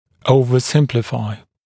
[ˌəuvə’sɪmplɪfaɪ][ˌоувэ’симплифай]чрезмерно упрощать, понимать слишком упрощенно